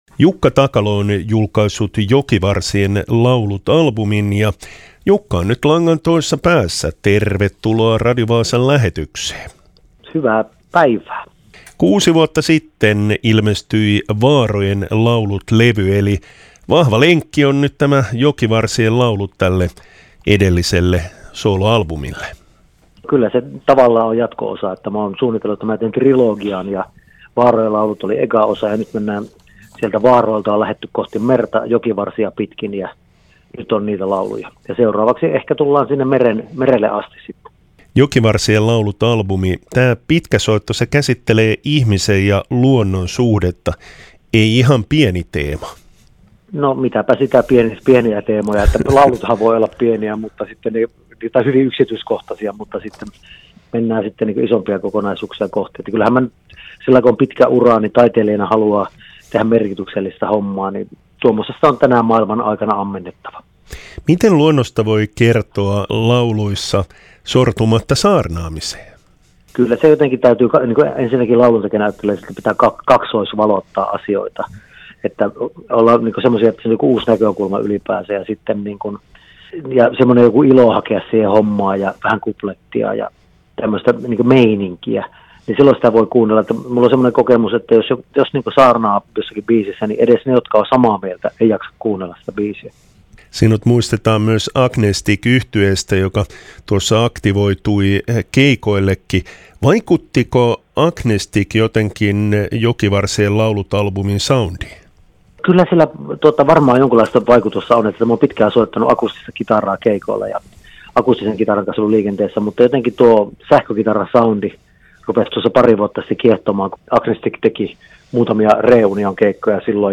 haastattelussa.